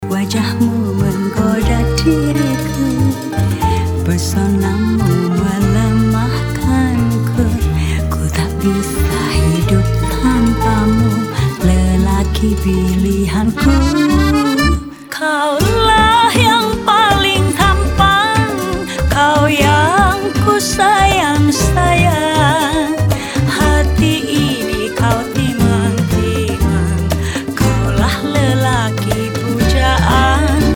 Easy listening.